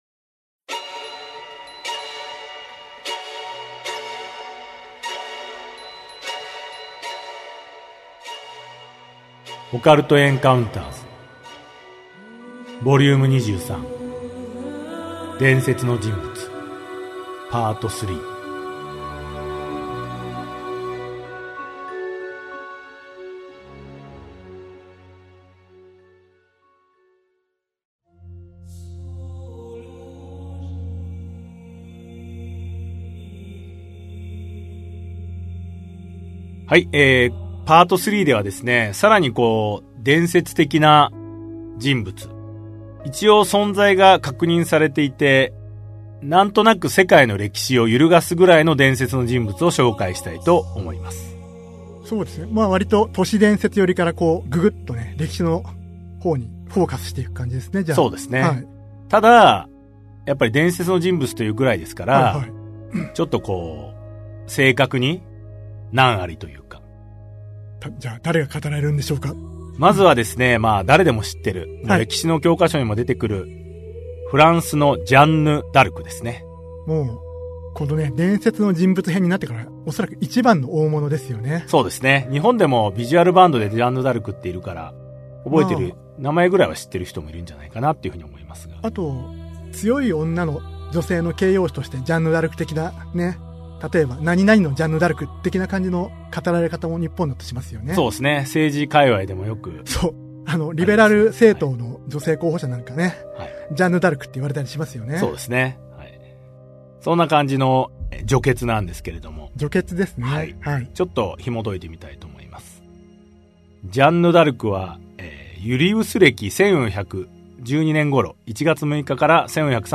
[オーディオブック] オカルト・エンカウンターズ オカルトを推理する Vol.23 伝説の人物編 3